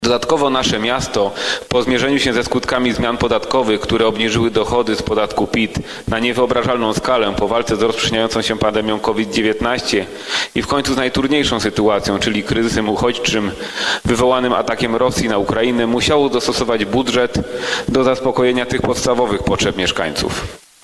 Realizację budżetu w 2022 roku w warunkach "kryzysu geopolitycznego i gospodarczego". Referował radny Maksymilian Pryga.